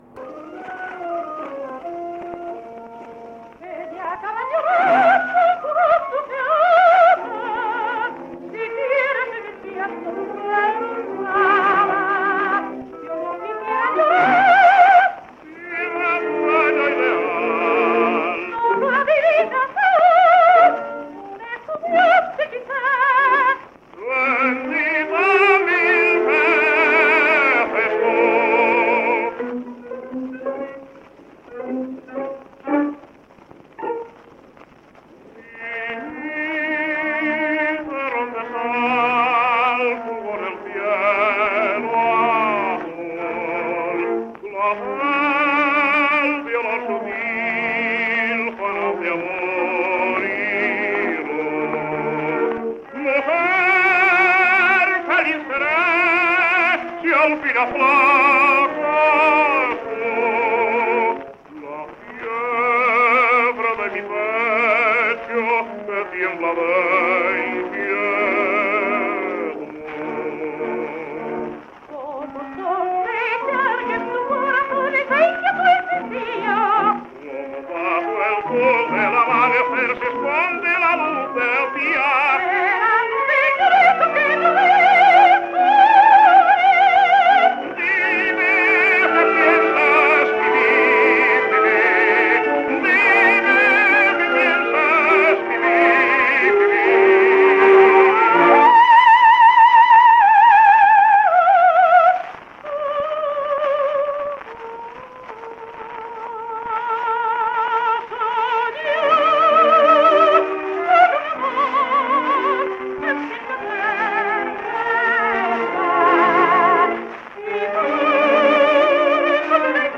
[78 rpm]